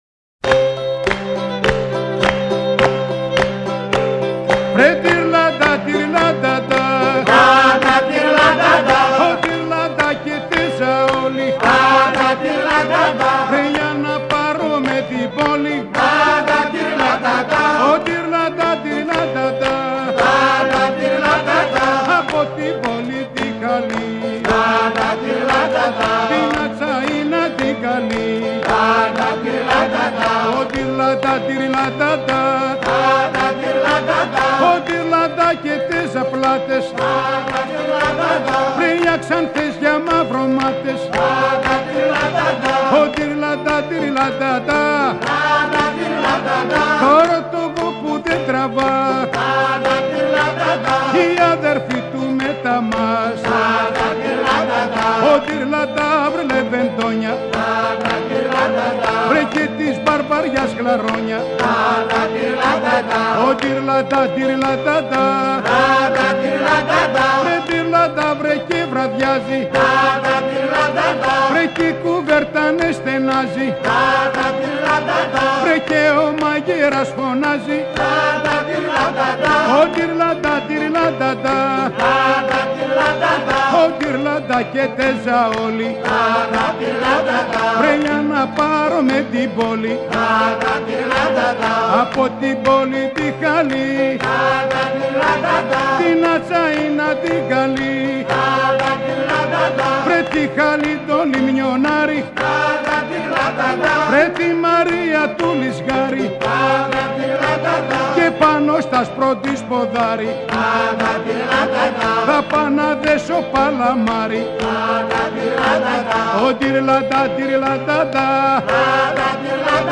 Dirlada, which we encounter in several versions and in a number of variants, is a work song, which might be sung oil-press to hearten the workers tolling at the hand-press of the time, or in the boats during the processing of sponges, or when pulling the oars.
The song is begun by one singer, the rest of the group joining in at the "break" and repeating the words.
The purpose of Dirlada was to encourage sponge divers and to give them the rhythm while oaring.